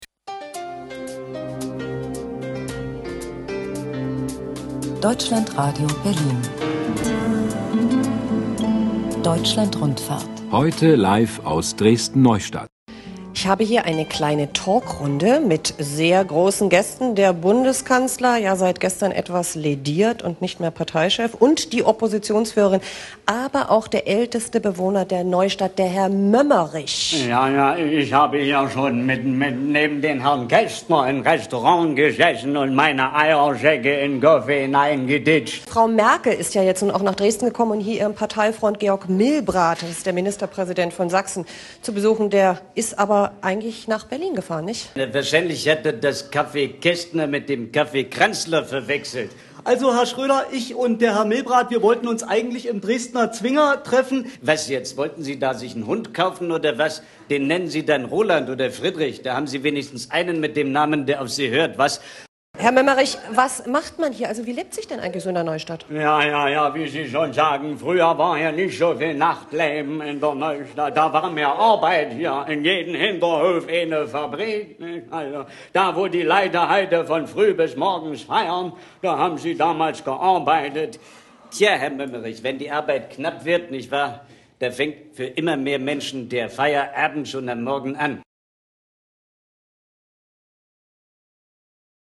Geschichten, Kabarettszenen, Parodien und Minihörspiele.